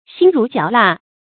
興如嚼蠟 注音： ㄒㄧㄥ ㄖㄨˊ ㄐㄧㄠˊ ㄌㄚˋ 讀音讀法： 意思解釋： 謂毫無興味。